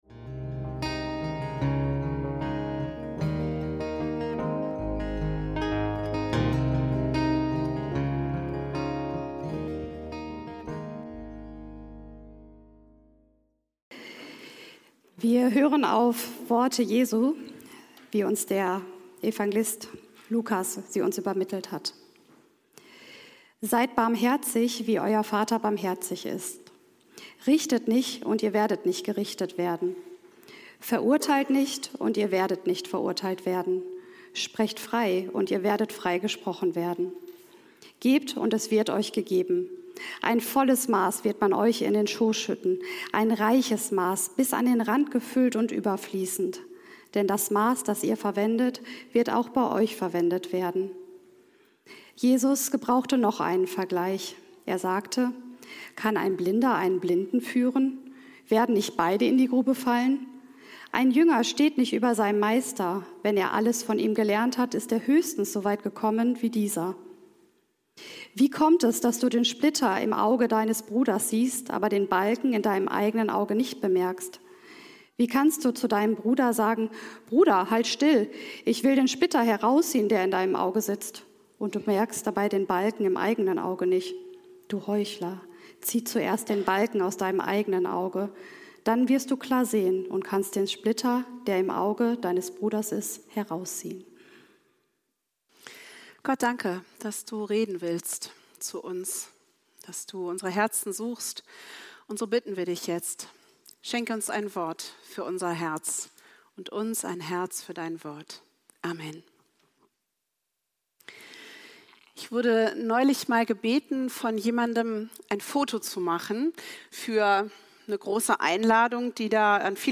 Seid barmherzig! - Predigt vom 15.03.2026 ~ FeG Bochum Predigt Podcast